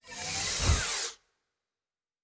pgs/Assets/Audio/Sci-Fi Sounds/Doors and Portals/Door 10 Close.wav at 7452e70b8c5ad2f7daae623e1a952eb18c9caab4
Door 10 Close.wav